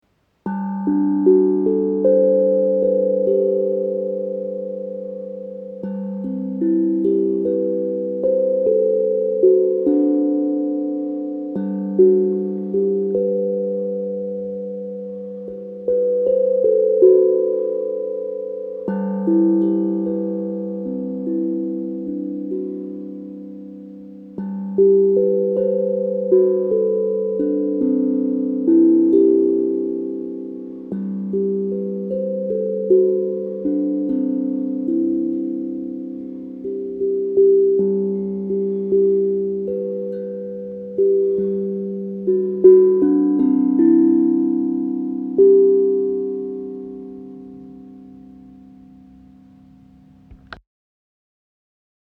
Tongue Drum • Lydyan • 9 notes
Le tongue drum, également appelé tank drum est un instrument de percussion mélodique qui produit des sons doux et apaisants.
9 Notes
Gamme : mode Lydyan
lydian-9-notes-tongue-drum.mp3